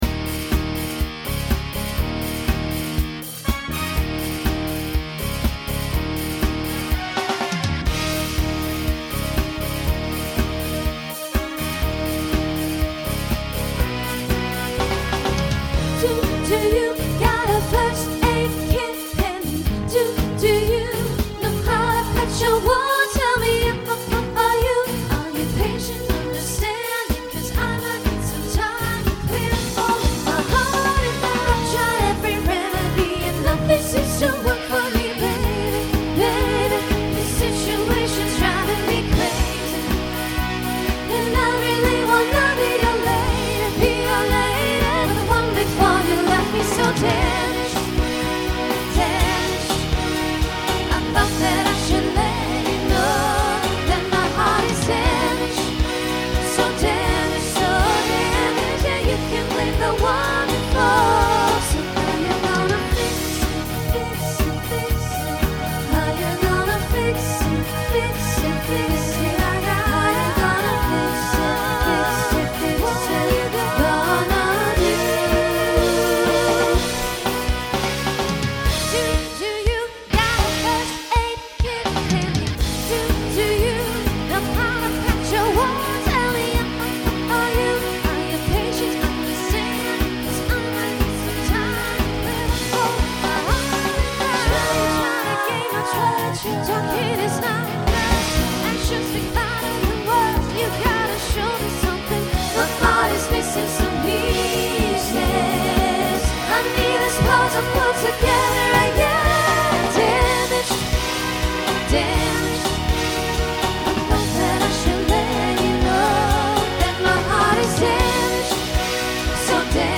Voicing SSA Instrumental combo Genre Rock